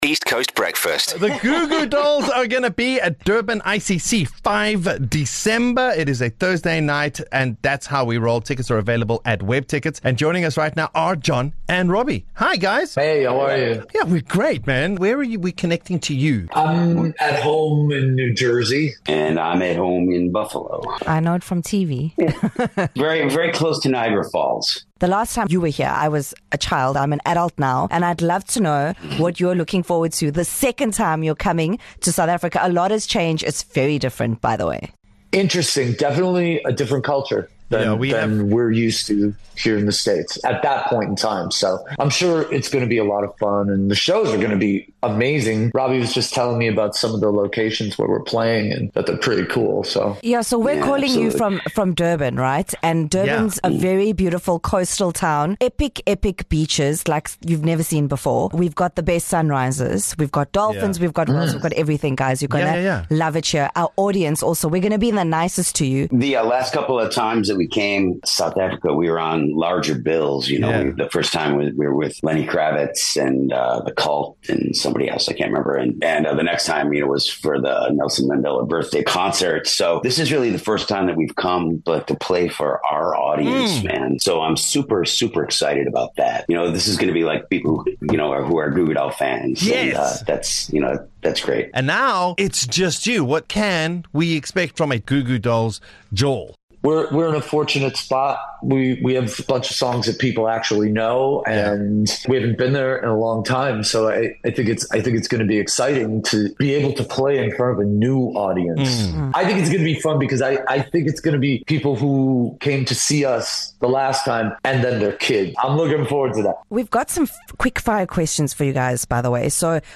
The Goo Goo Dolls full interview with East Coast Breakfast